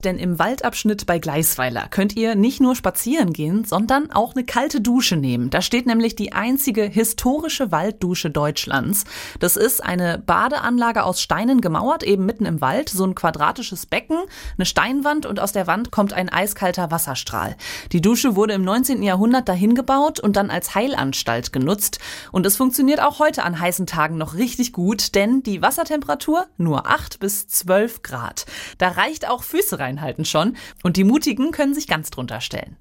Nachrichten „Das funktioniert auch heute noch richtig gut“